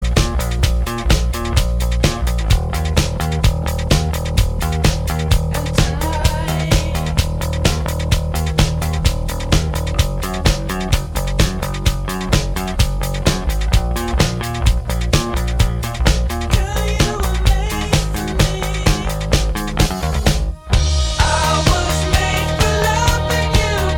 Minus Solo Guitars For Guitarists 5:21 Buy £1.50